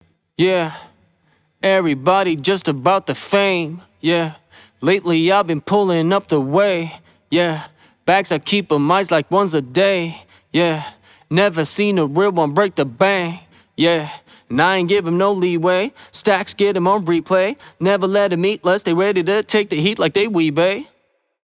Singer after Vocal Cleanup
Rap_cleanup.mp3